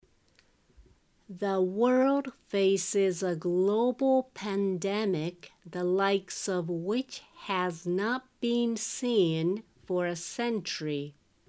ゆっくり：